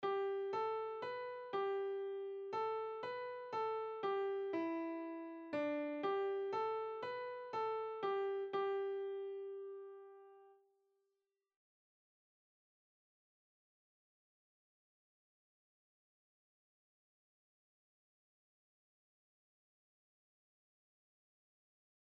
rg-229-halleluja-(gregorianisch).mp3